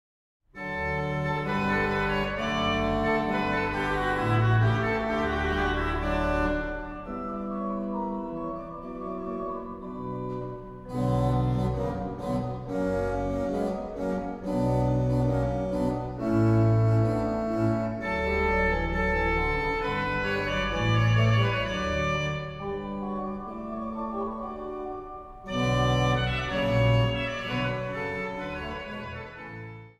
Instrumentaal | Orgel